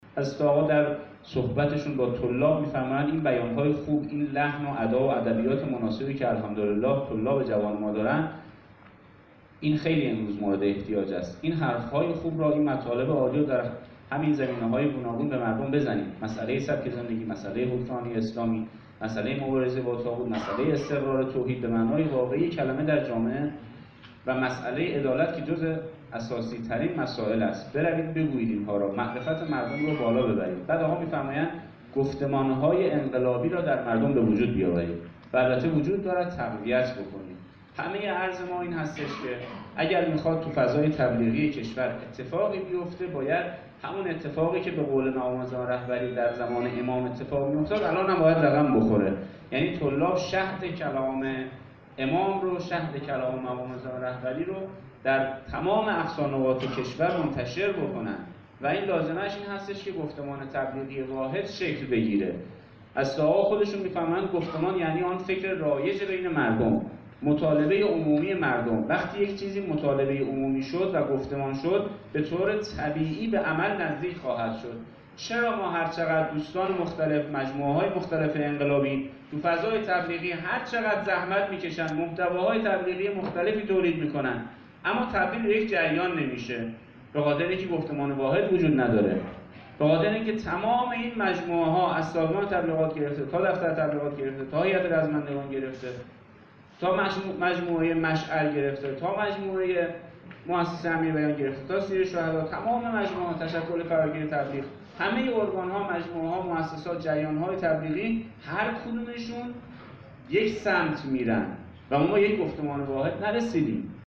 در همایش «گفتمان تبلیغی فاطمیه» که در سالن همایش های اداره کل تبلیغات اسلامی استان قم برگزار شد